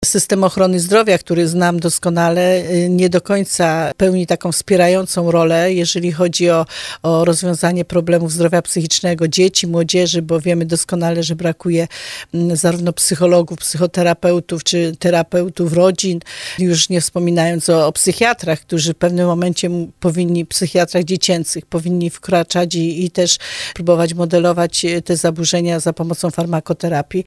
To tragedia, w której złamane zostały dwa życia – takimi słowami poseł PiS Józefa Szczurek-Żelazko odniosła się na antenie RDN Małopolska do wydarzeń, które rozegrały się w tarnowskim Lasku Lipie.
Józefa Szczurek-Żelazko w audycji Słowo za Słowo zwracała uwagę, że współczesna młodzież, obciążona nawałem zajęć dodatkowych, a przy tym mocno zamknięta w świecie wirtualnym, jest bardzo samotnym pokoleniem.